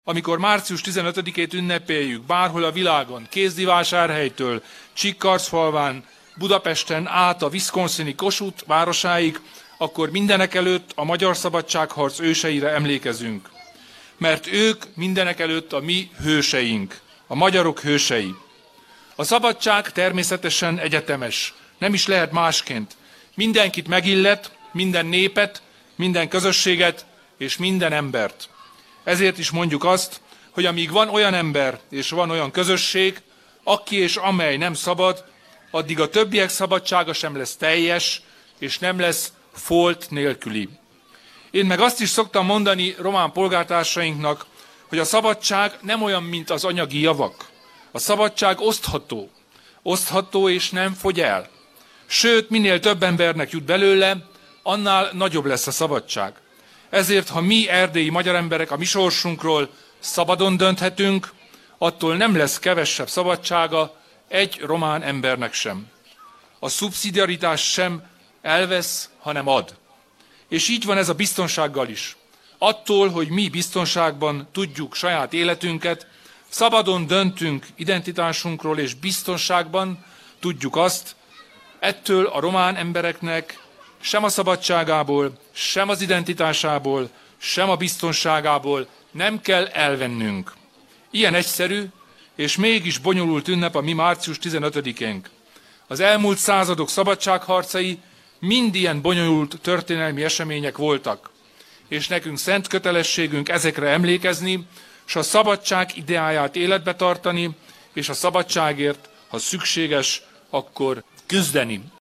Az erdélyi magyarság sosem fogadta el a méltánytalanságot és elnyomást, egyetértésre törekedett a román többséggel, és olyan jövő építésére törekszik, amelyben helye van minden etnikumnak – hangoztatta a magyar nemzeti ünnep alkalmából Csíkszeredában Kelemen Hunor, az RMDSZ elnöke.